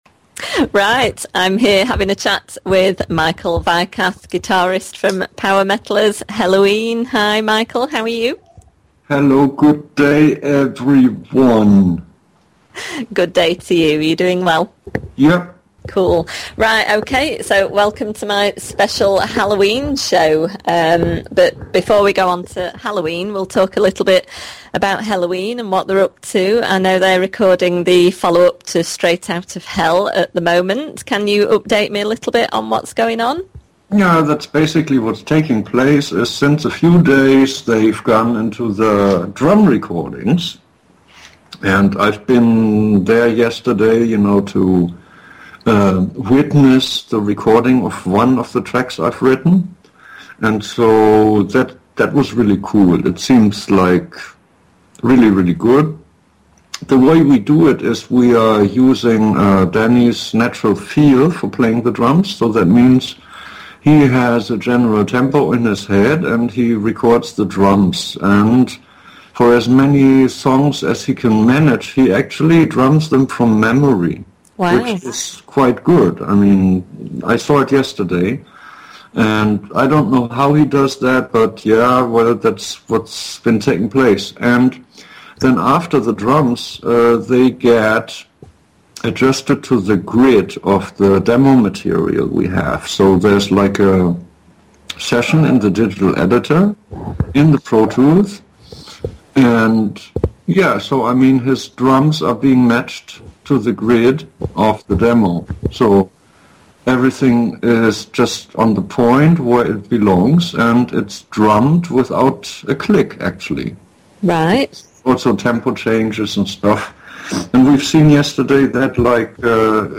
michael-weikath-interview-autumn-2014.mp3